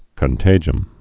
(kən-tājəm)